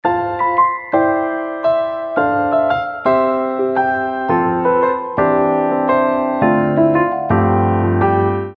• Качество: 320, Stereo
красивые
милые
без слов
пианино
Милая мелодия на любой случай